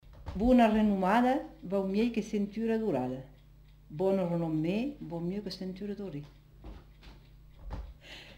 Lieu : Cathervielle
Effectif : 1
Type de voix : voix de femme
Production du son : récité
Classification : proverbe-dicton